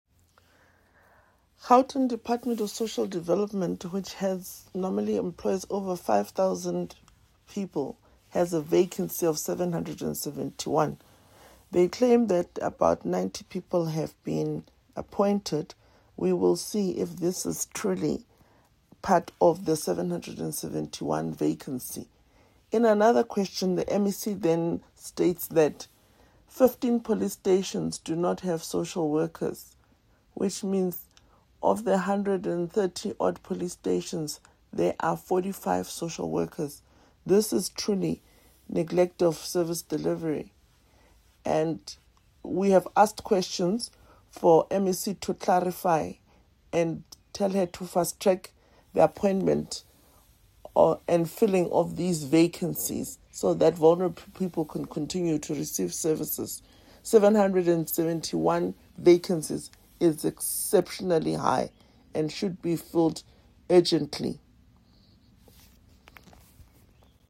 Note to Editors: Attached please find a soundbite from DA MPL, Refiloe Nt’sekhe in English